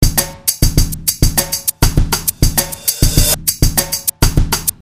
描述：突破，手鼓，轻松
Tag: 100 bpm Rock Loops Drum Loops 826.99 KB wav Key : Unknown